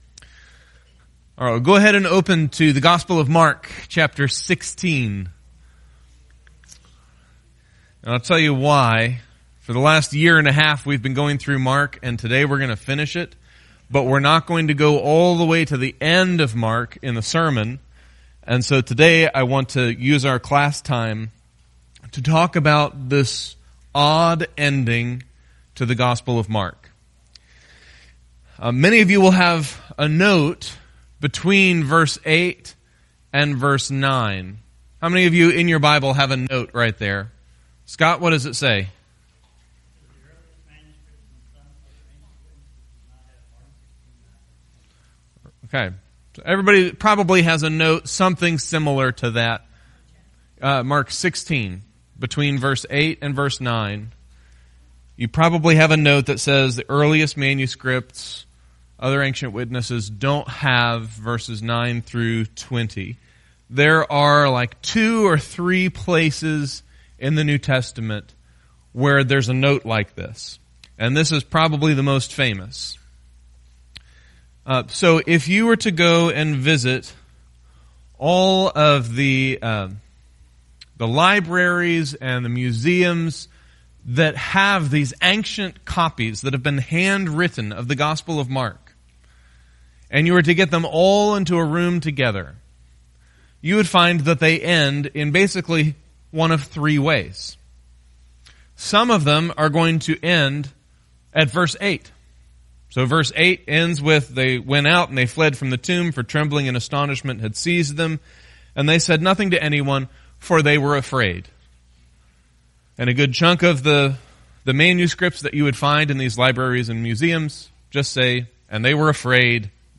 This morning, we finish a 14-month sermon series in the Gospel of Mark.